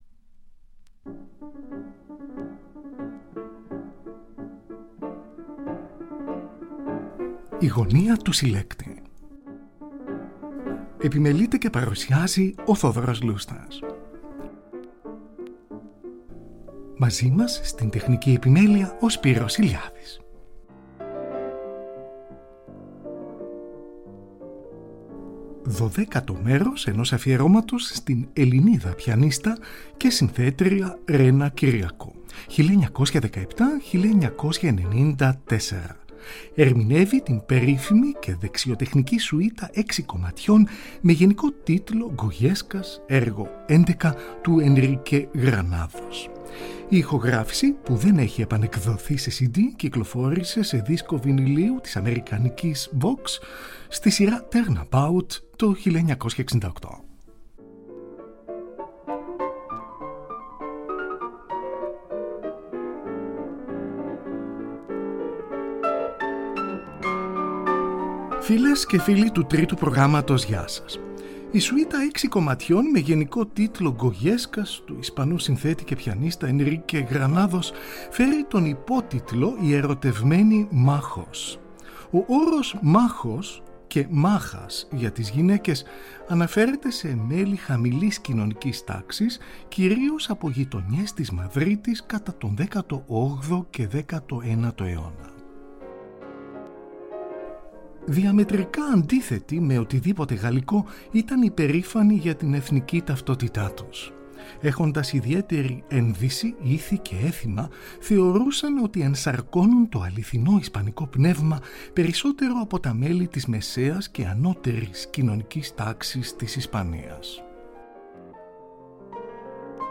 Αφιέρωμα στην Ελληνίδα Πιανίστα & Συνθέτρια
Εργα για Πιανο